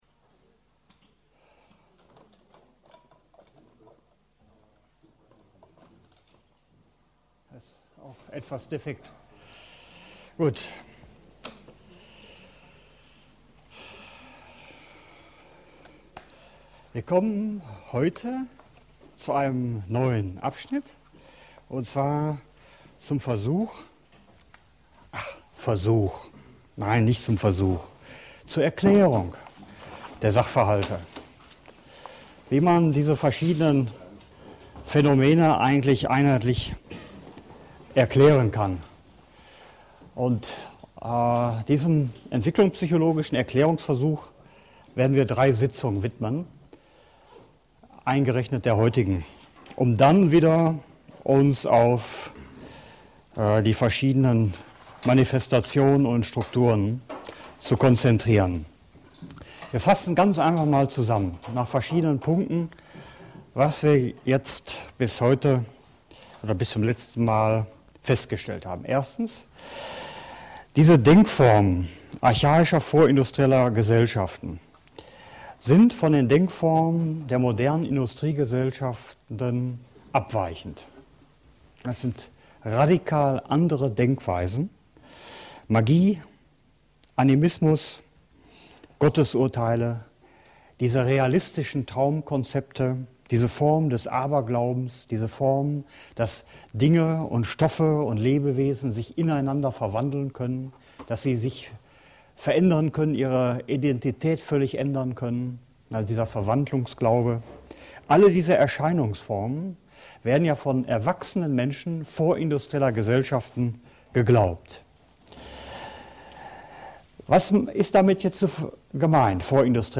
Genre Vorlesung